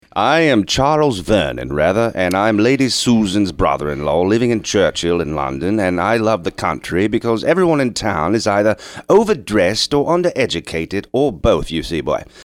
got into character when describing his role.